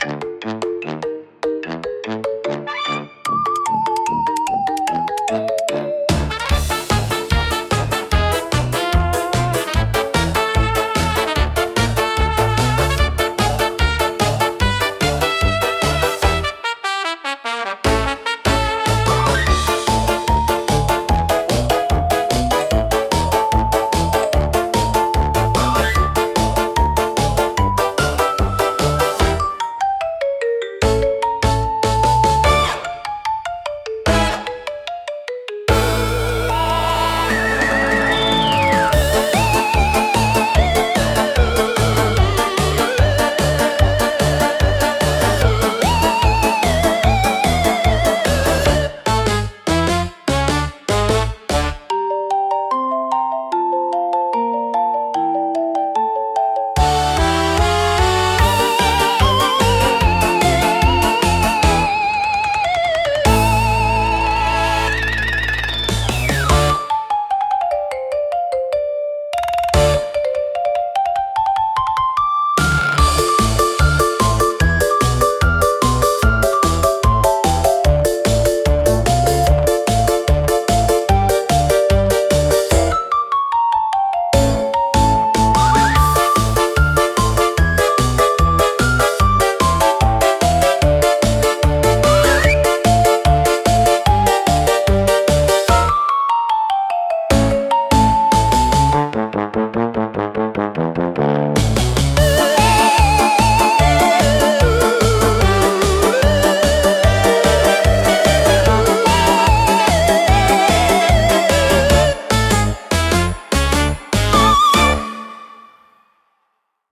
聴く人に軽やかで楽しい気分を届け、明るく気楽な空気を作り出します。